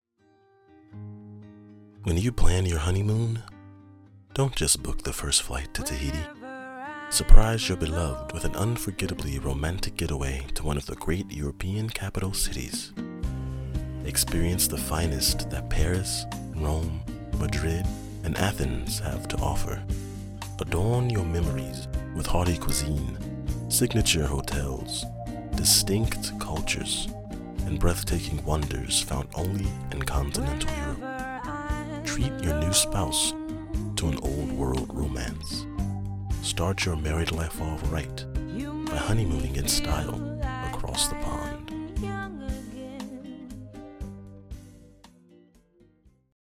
Romantic Commercial
US Southern, US General
Young Adult